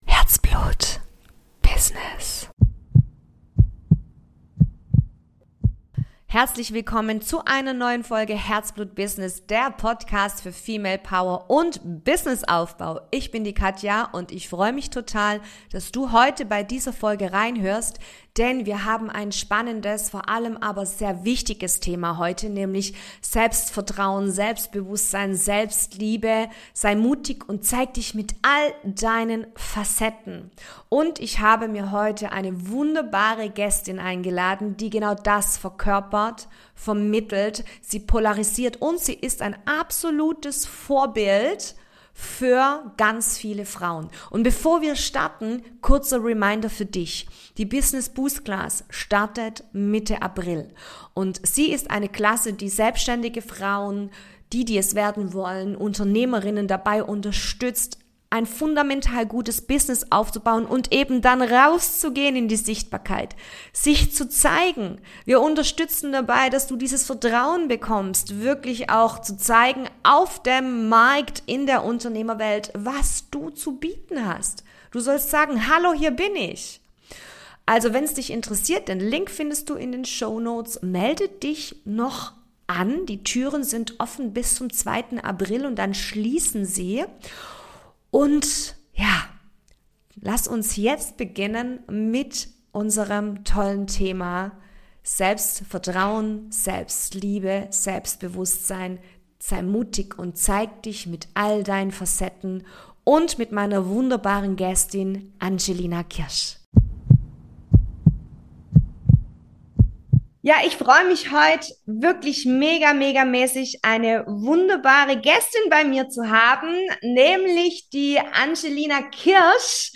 In meinem neuen Podcast-Interview habe ich die unglaublich inspirierende Powerfrau Angelina Kirsch zu Gast.